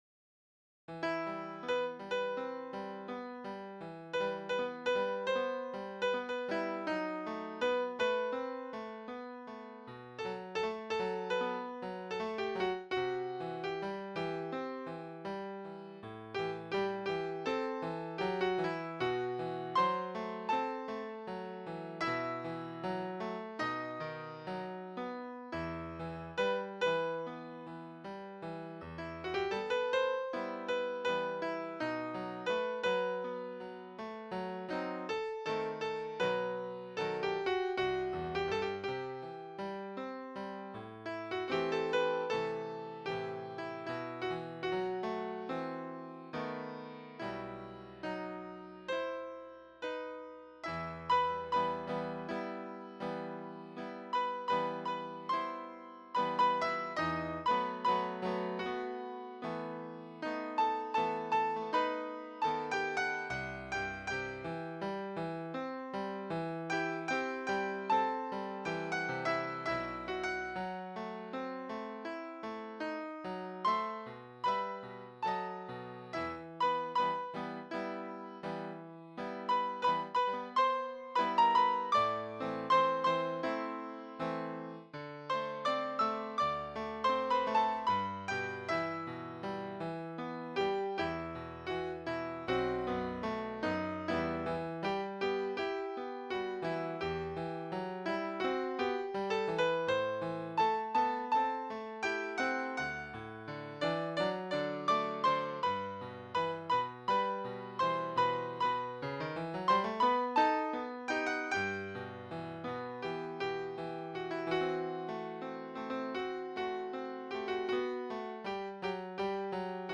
Partitura para piano / Piano score (pdf)
Easy piano score